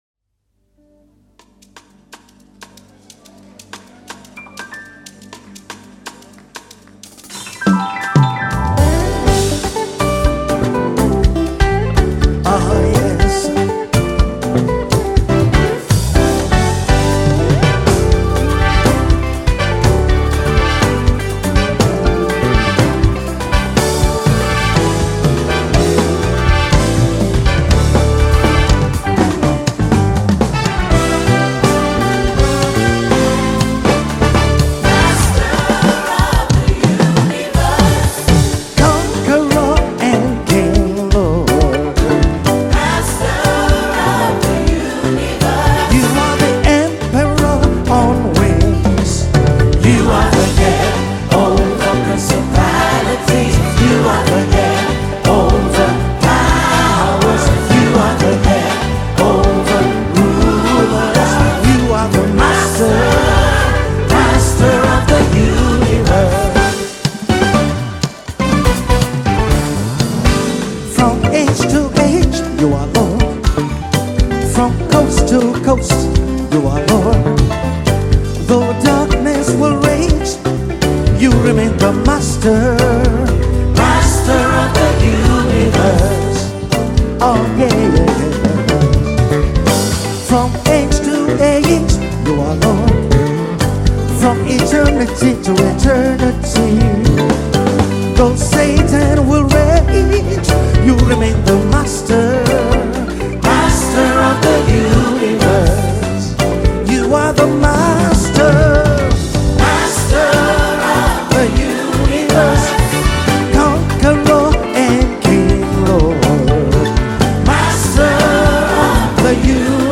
March 19, 2025 Publisher 01 Gospel 0